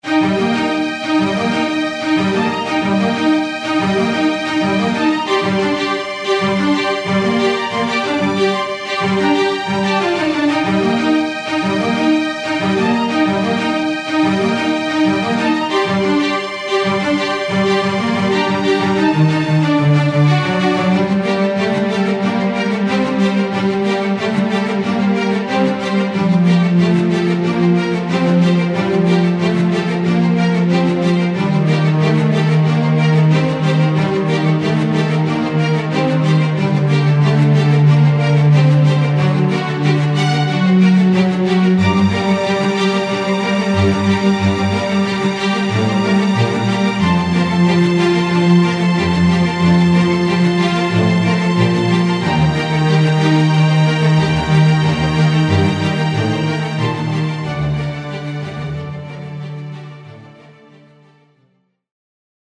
HALion6 : Studio Strings
●Allegretto●Arco●Esolanade